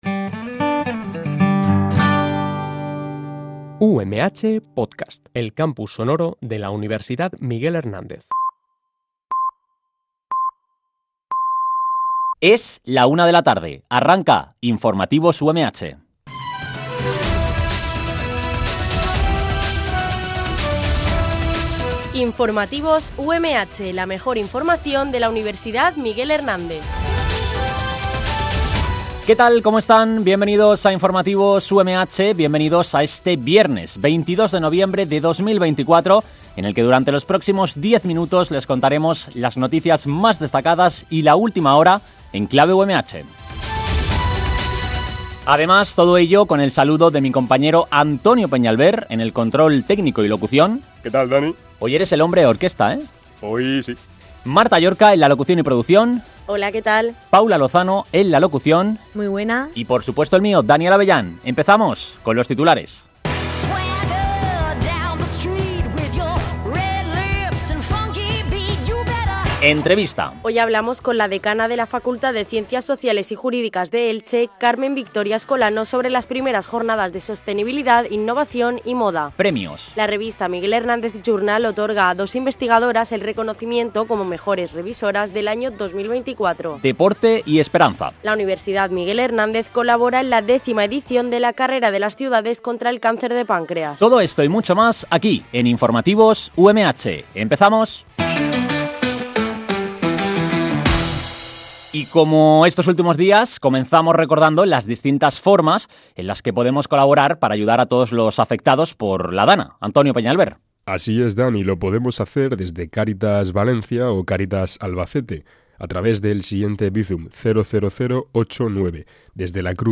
Este programa de noticias se emite de lunes a viernes